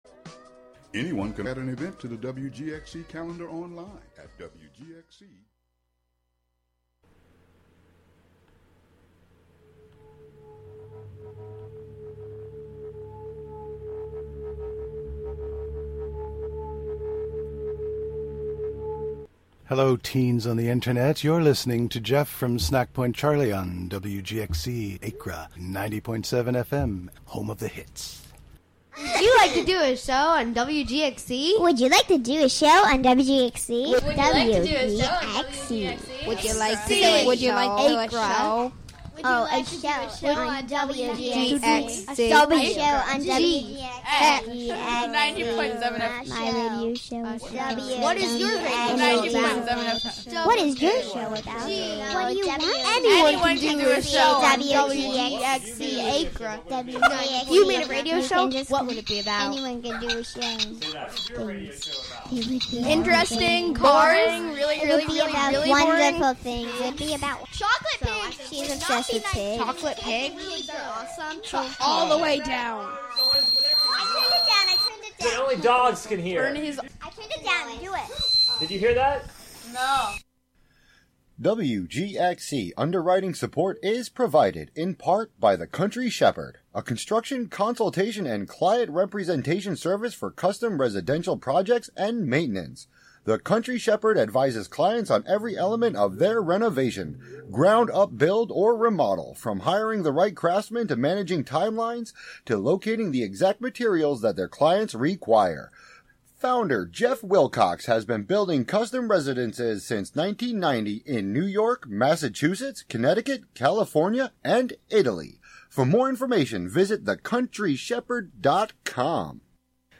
This month's broadcast theme highlights its own medium: air. We'll listen to songs and sounds inspired by and made from air as element, instrument, conduit.
With a blend of song, field recording, archival audio, and conversation, the show explores the thin spaces between the ordinary and sacred, human and nonhuman, particular and universal, and visible and invisible, through a different sonic theme and/or medium each month.